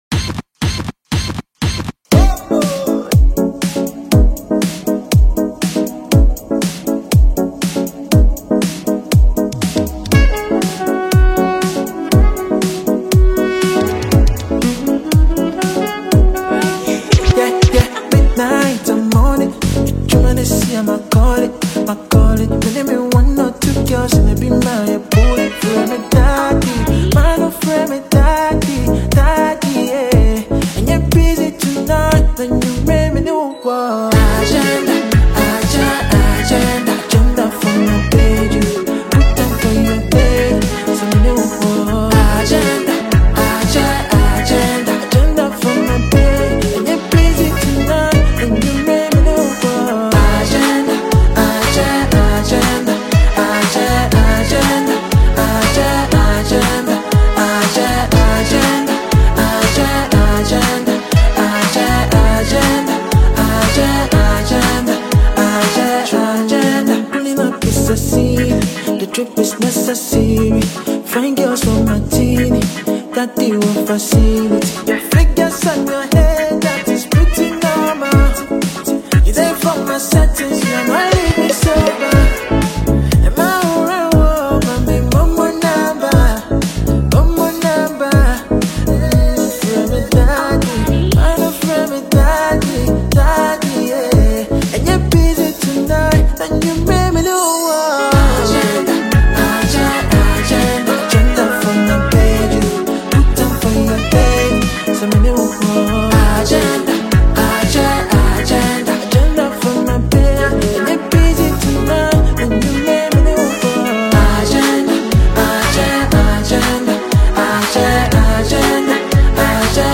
Afro-Drill